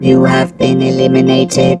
rick_kill_vo_04.wav